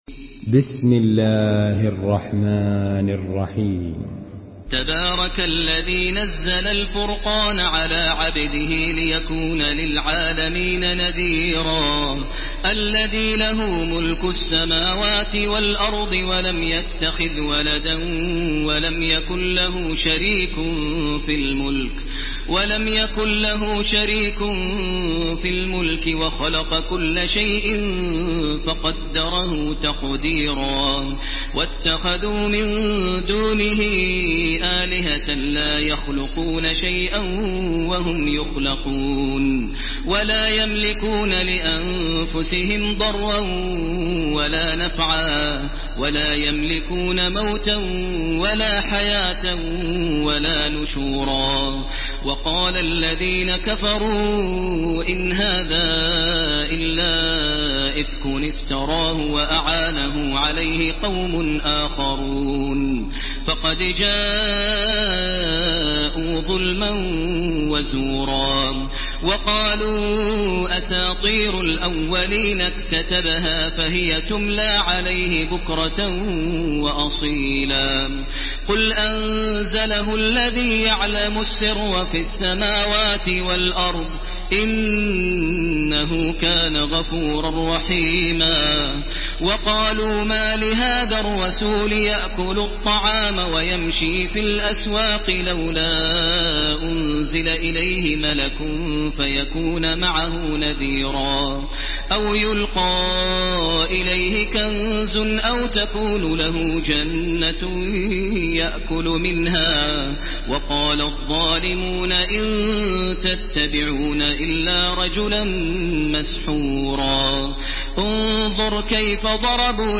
Surah Al-Furqan MP3 by Makkah Taraweeh 1430 in Hafs An Asim narration.
Murattal